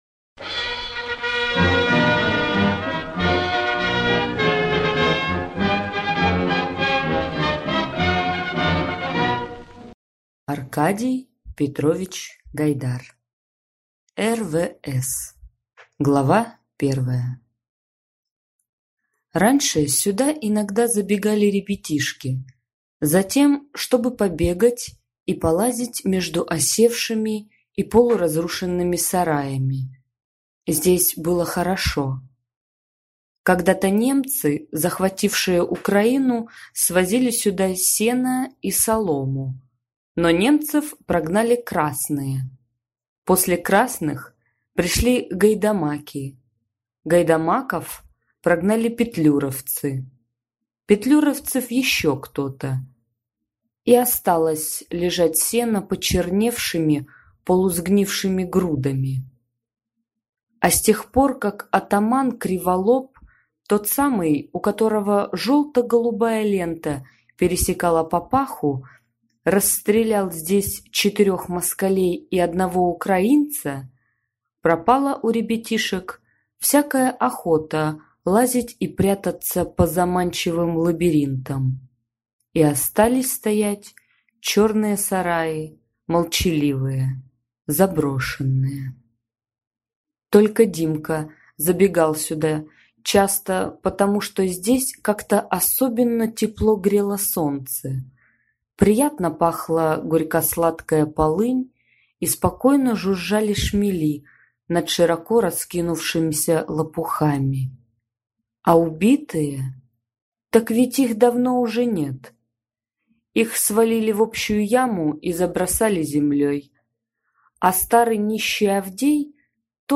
Аудиокнига Р.В.С. | Библиотека аудиокниг